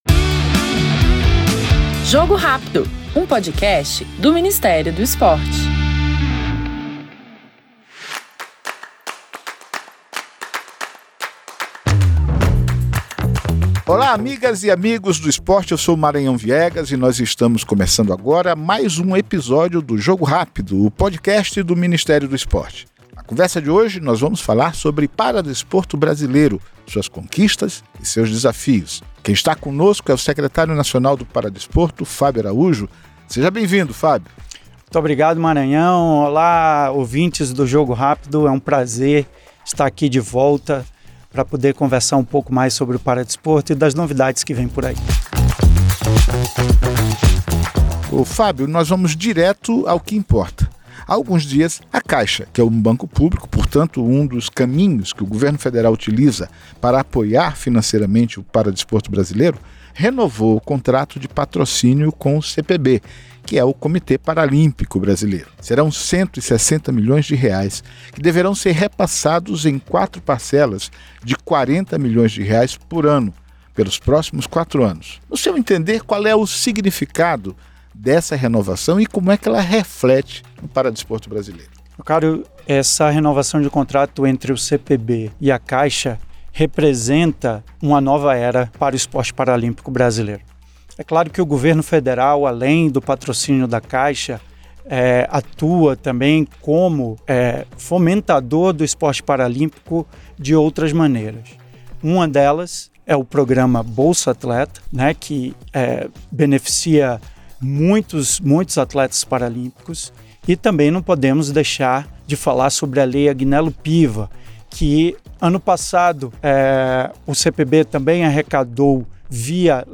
A presidente da ABCD – Autoridade Brasileira de Controle de Dopagem, Adriana Taboza, é a entrevistada do novo episódio do podcast Jogo Rápido e detalha a importância do Dia Mundial do Jogo Limpo.